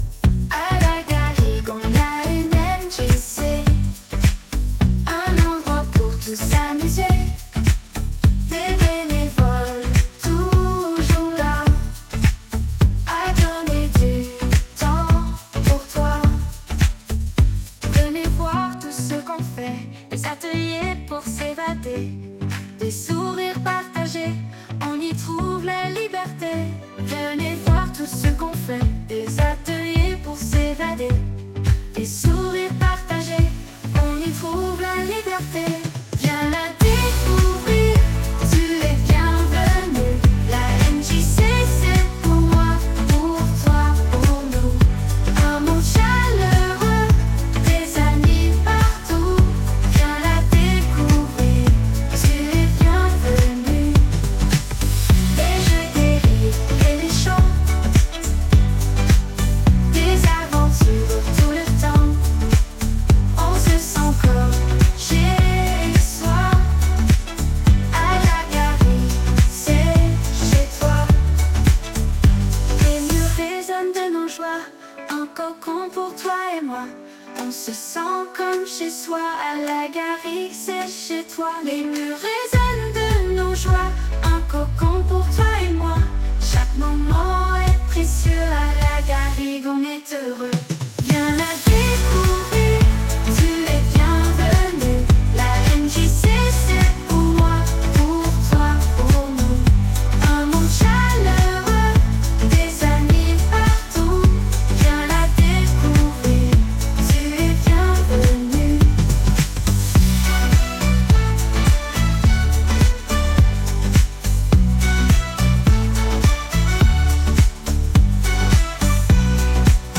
Une petite chanson modeste, qui marche à pas tranquilles, comme les gens qui la traversent.